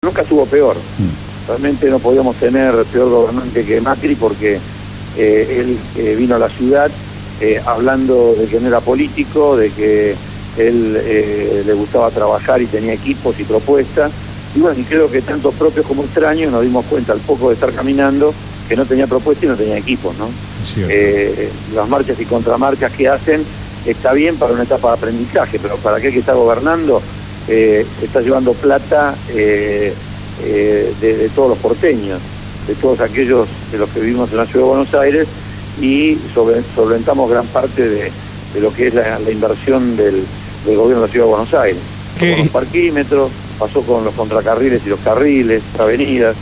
Fueron los dichos de Abel Fatala, Subsecretario de Obras Públicas de la Nación que fue entrevistado en el programa «Abramos la boca» (Lunes a viernes 16 a 18hs.) por Radio Gráfica.